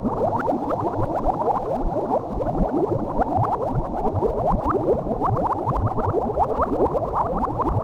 potion_bubbles_brewing_loop_05.wav